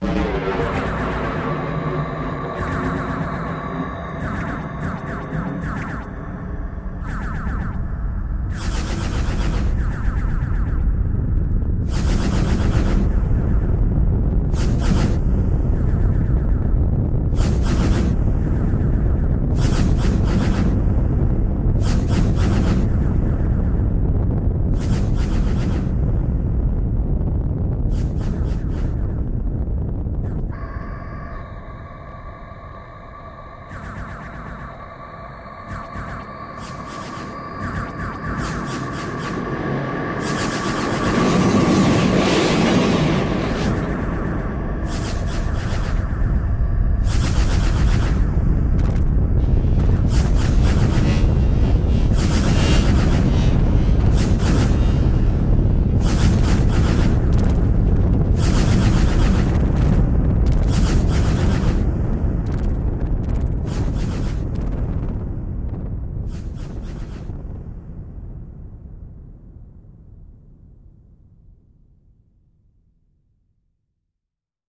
Звук боевой перестрелки звездолетов